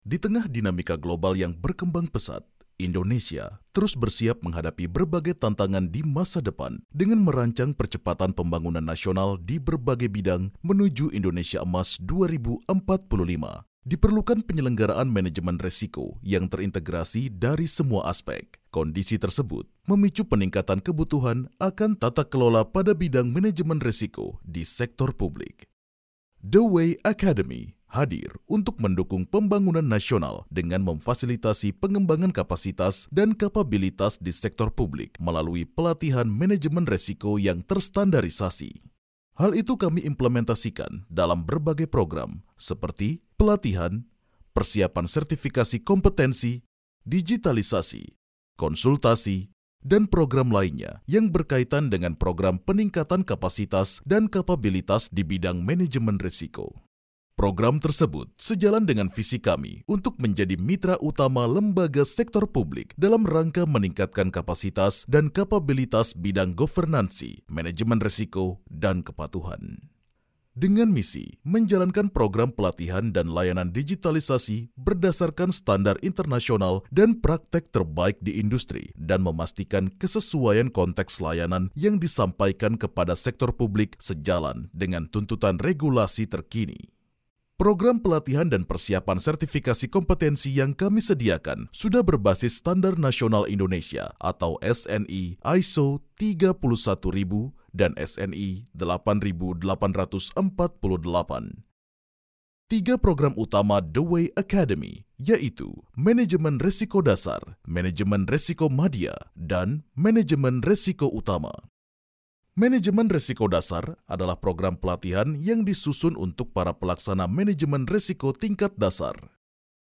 Kommerziell, Cool, Vielseitig, Erwachsene, Warm
Unternehmensvideo